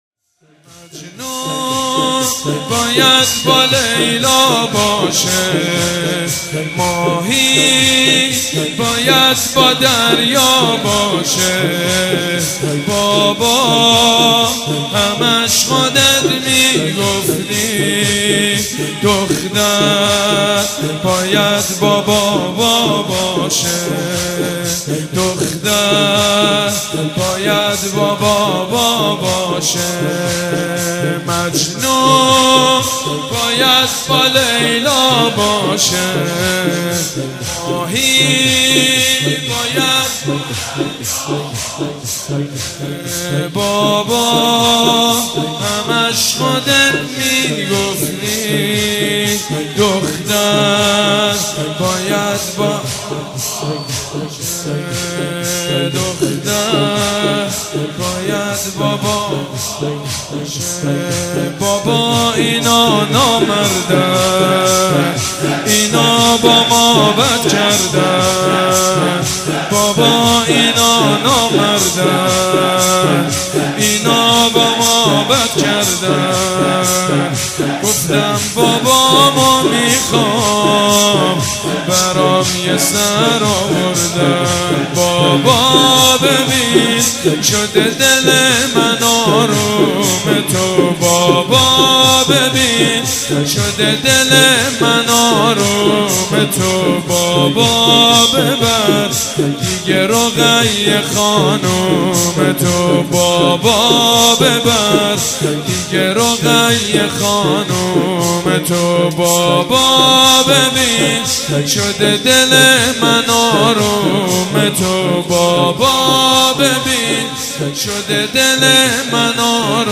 حقا که حقی و به نظرها نیاز نیست واحد محمود کریمی